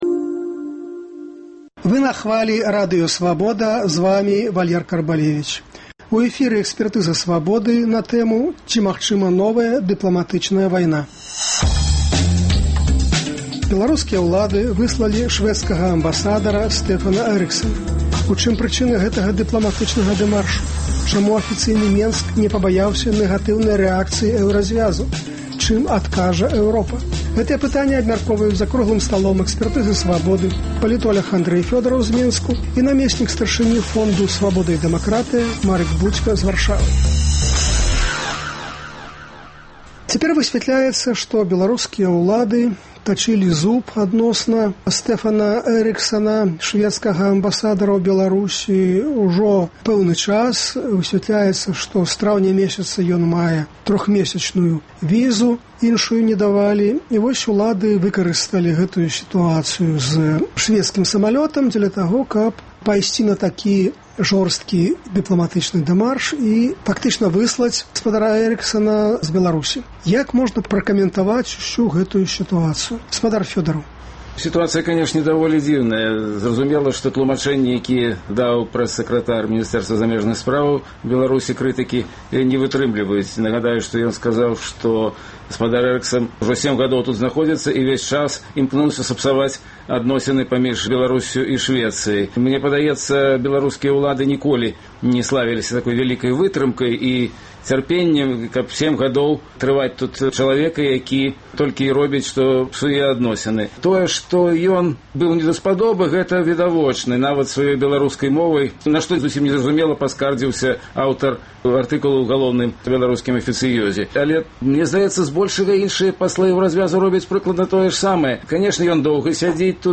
Чым адкажа Эўропа? Гэтыя пытаньні абмяркоўваюць палітоляг